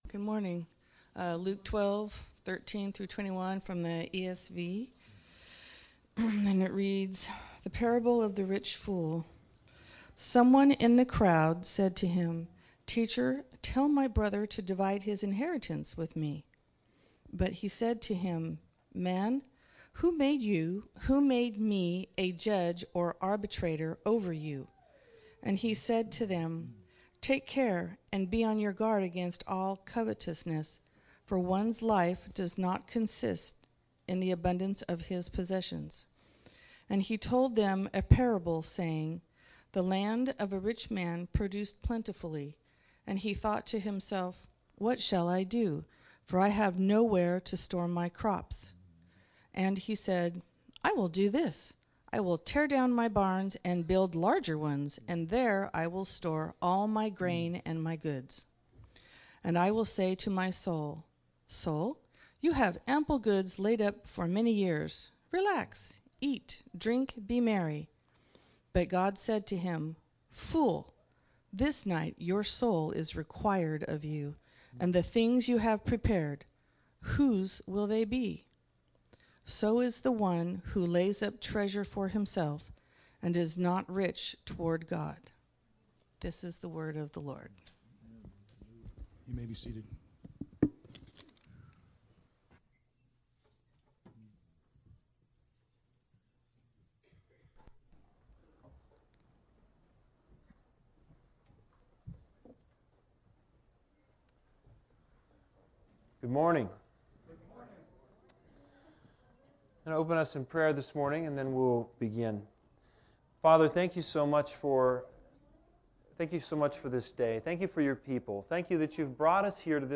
Series: Special Sermon